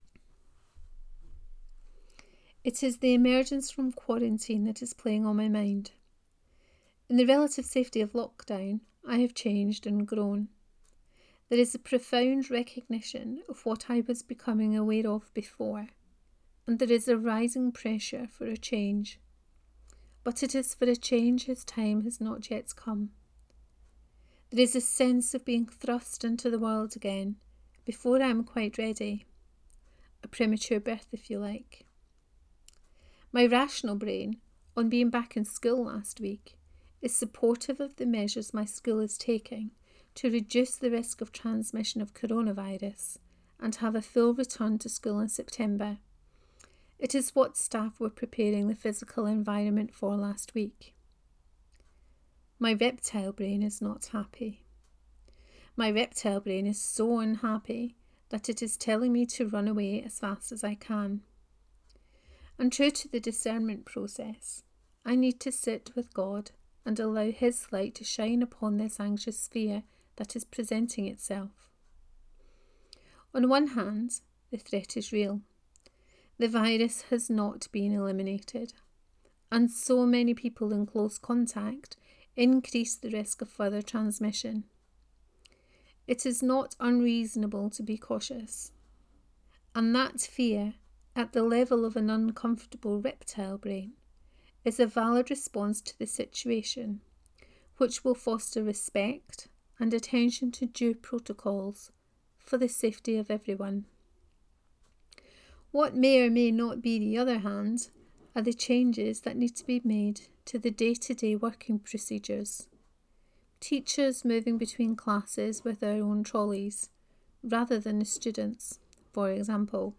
On Being in the Cave 4: Reading of this post.